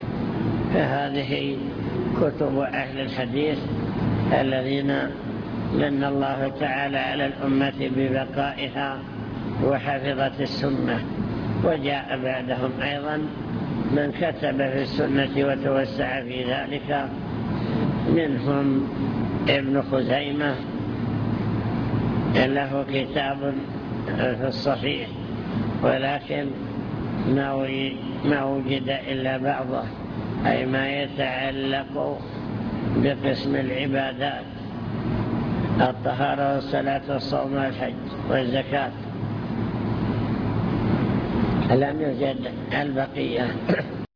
المكتبة الصوتية  تسجيلات - محاضرات ودروس  محاضرات بعنوان: عناية السلف بالحديث الشريف من جاء بعد أصحاب الكتب الستة